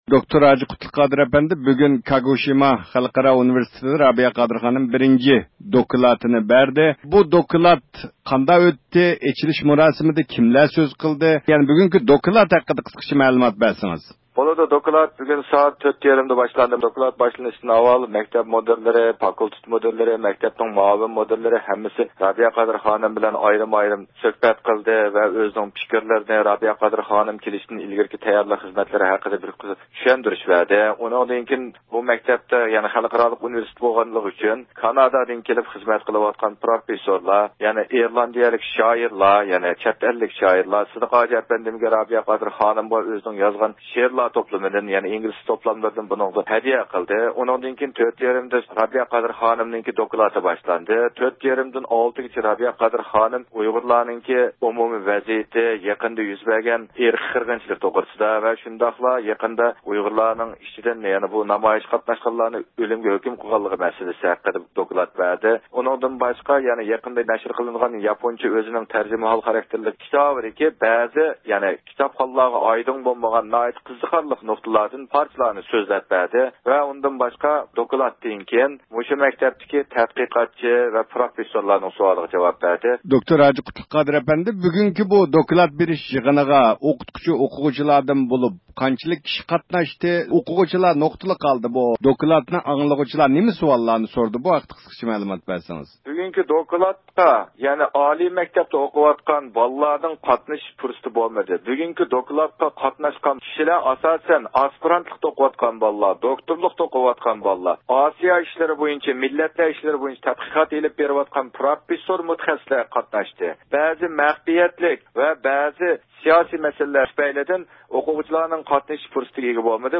بىز بۇ دوكلات بېرىش يىغىنى ھەققىدە مەلۇمات ئېلىش ئۈچۈن نەق مەيدانغا تېلېفون قىلىپ رابىيە قادىر خانىم ۋە باشقىلار بىلەن تېلېفون زىيارىتى ئېلىپ باردۇق.